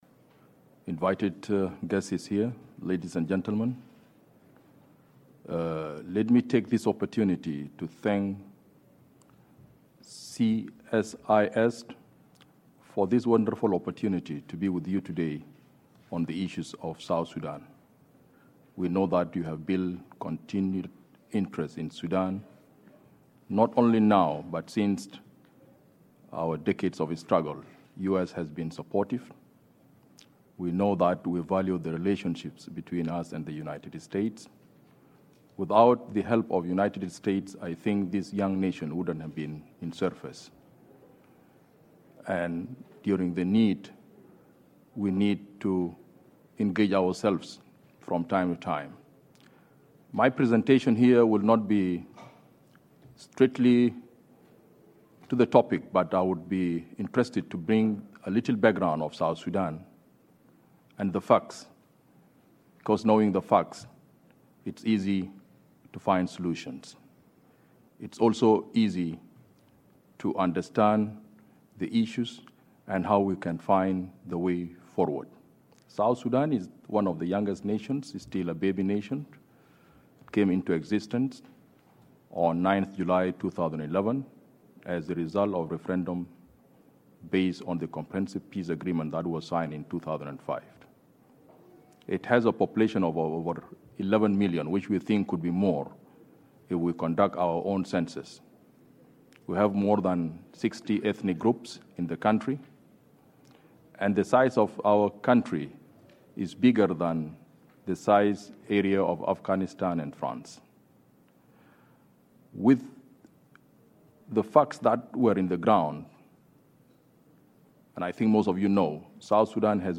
Awan Riak speech at CSIS, Washington DC, April 9, 2014